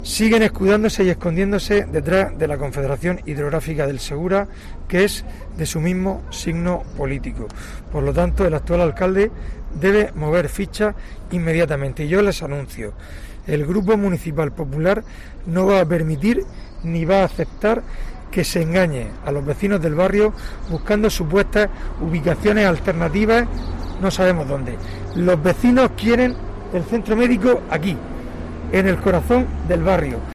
Fulgencio Gil, portavoz del PP sobre centro salud